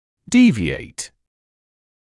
[‘diːvɪeɪt][‘диːвиэйт](…from) отклоняться от нормы, стандартов и пр.